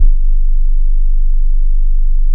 MAMOUTE BASS.wav